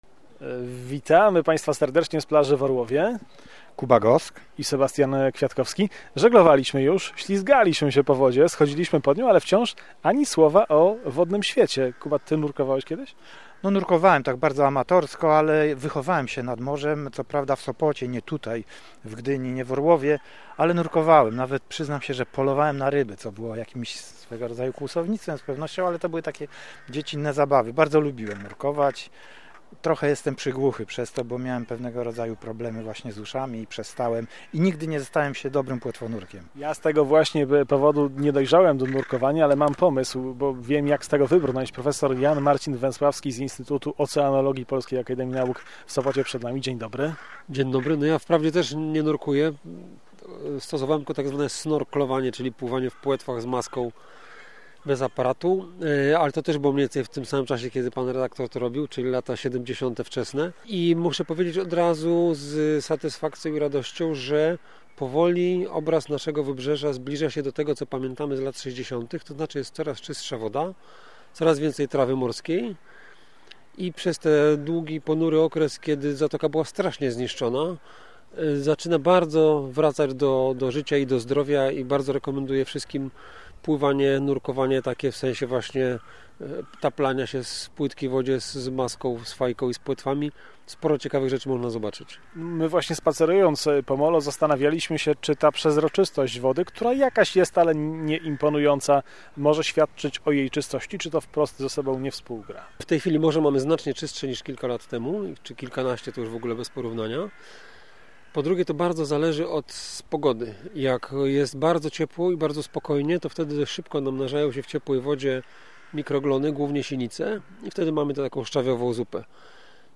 Piękna i unikatowa przyroda jest bliżej niż mogłoby się wydawać. Łączymy się z Orłowem, gdzie są nasi reporterzy.